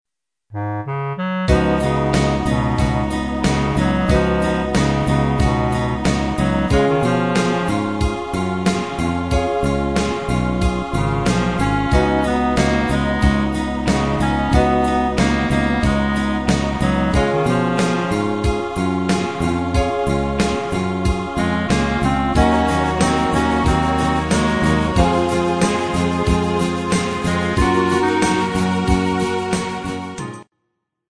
Pop
60s Rock